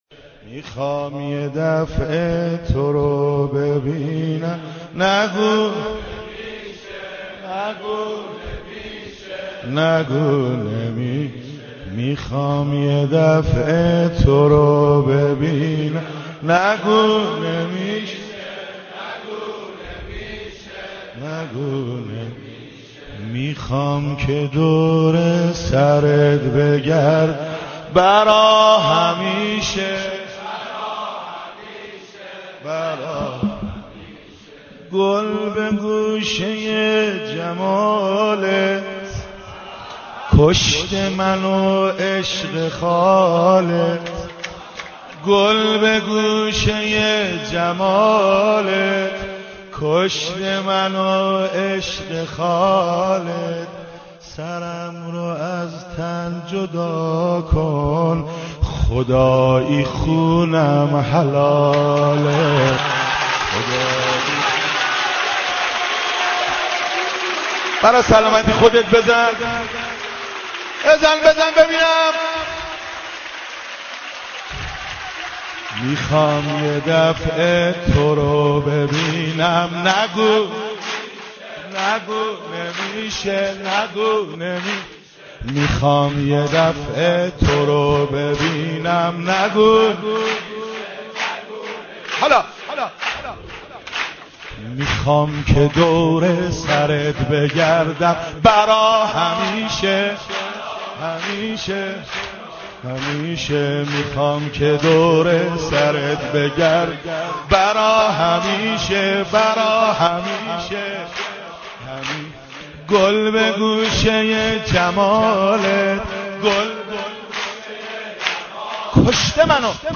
مولودی امام زماان(عج)
مولودی میلاد امام زمان